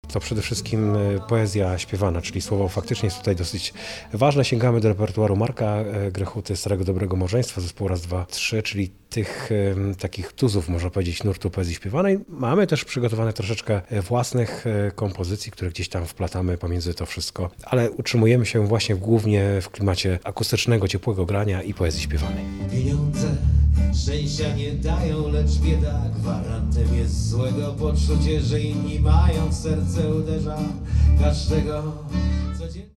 Dodajmy, że koncert literacki zatytułowany był „W objęciach czasu”.